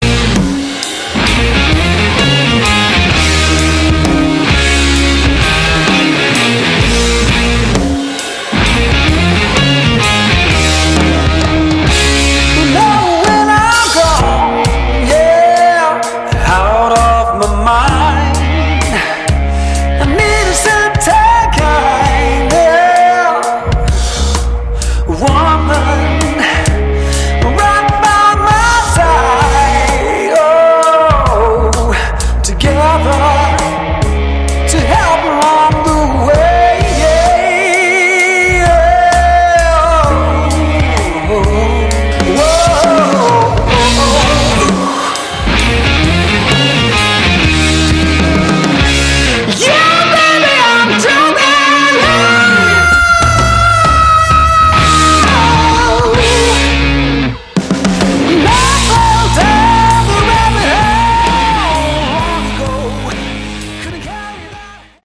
Category: Bluesy Hard Rock
vocals, harmonica
guitar, backing vocals
drums
bass